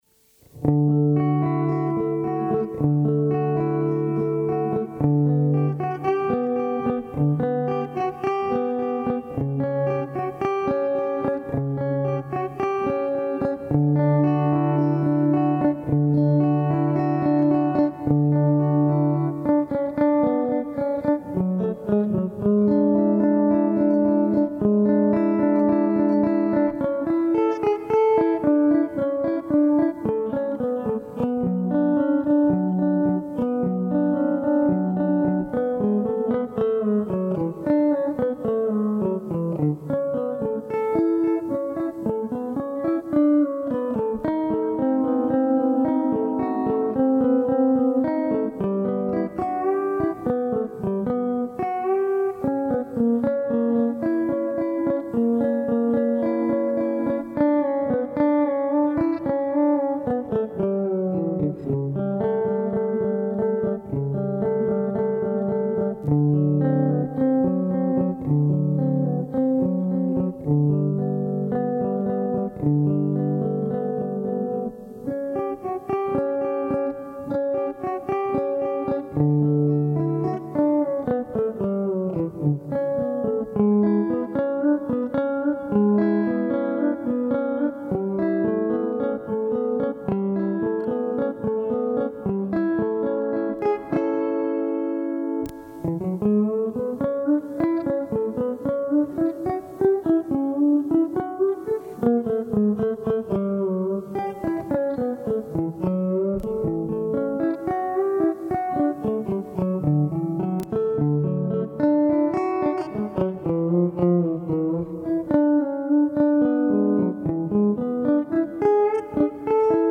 After listening to the music of Johann Sebastian Bach for many years I decided to explore his counterpoint and harmonies with my favorite instrument, the pedal steel.
I wanted to hear how it would sound on the pedal steel , so here it is.